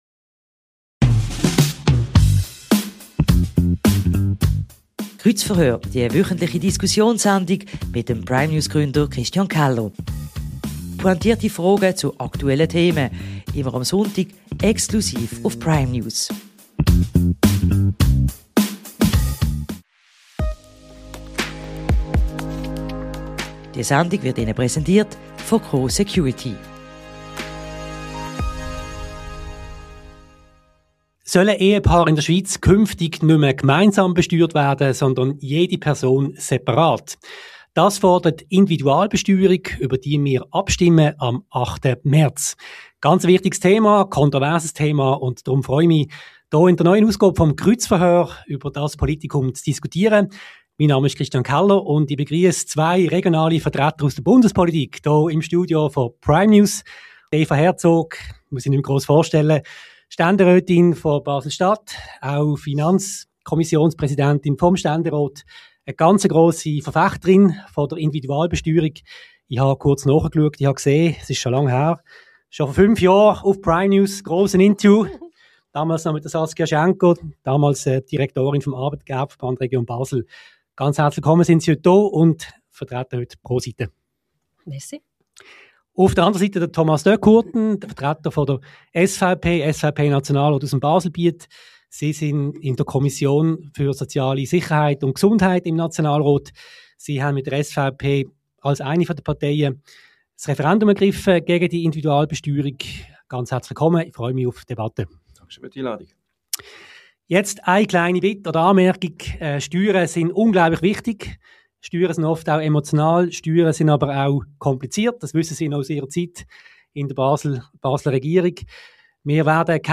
Es diskutieren die Basler Stände|rätin Eva Herzog und der Basel|bieter SVP-Nationalrat Thomas de Courten.